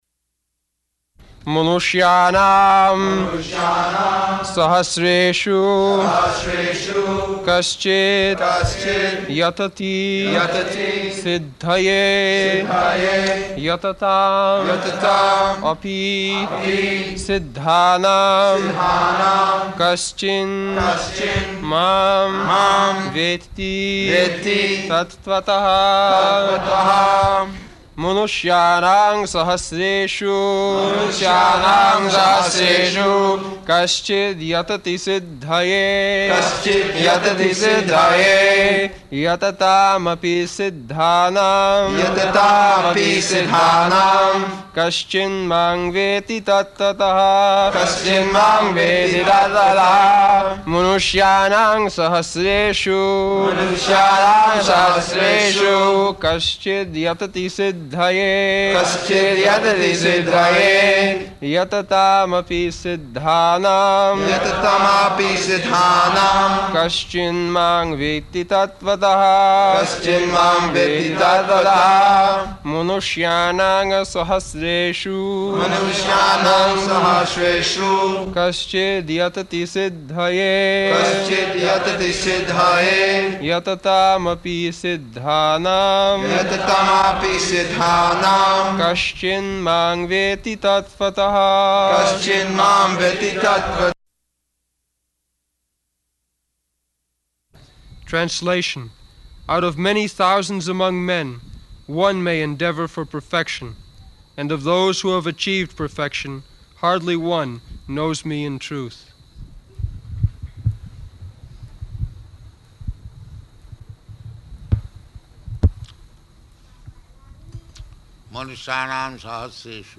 August 9th 1974 Location: Vṛndāvana Audio file